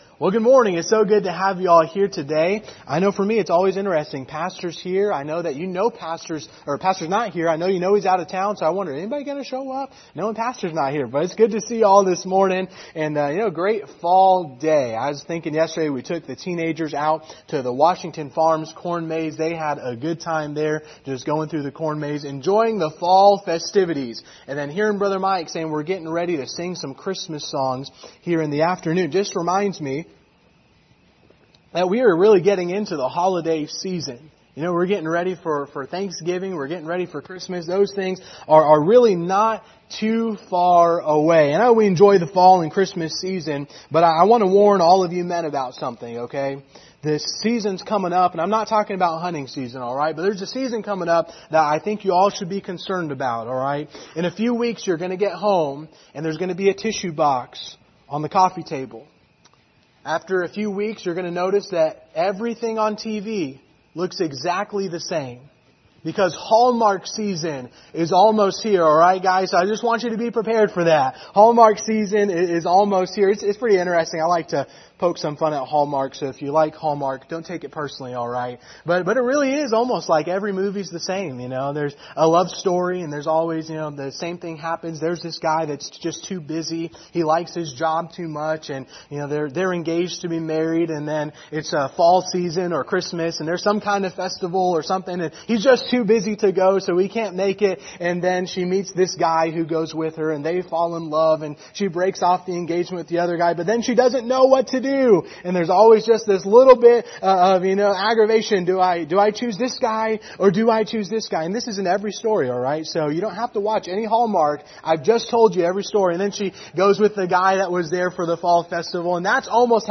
Passage: Hosea 1-3 Service Type: Sunday Morning